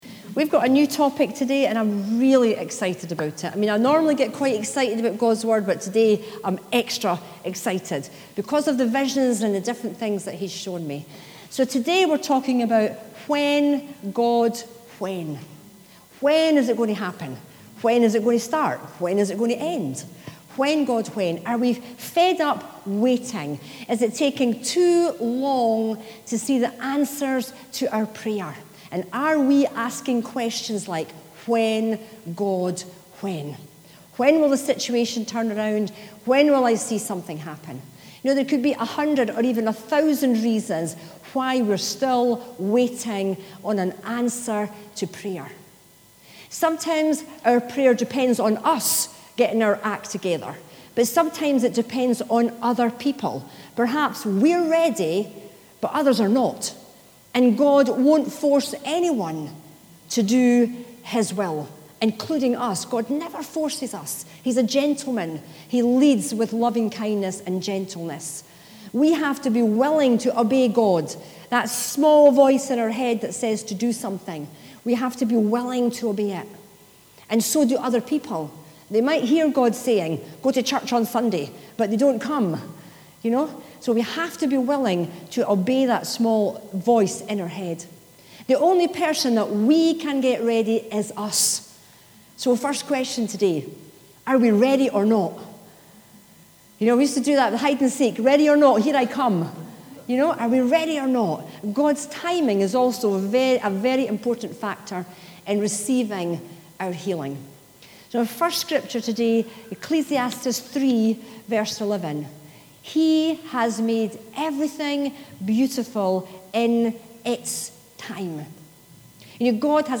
Sermons 2017 – Dunfermline Elim Pentecostal Church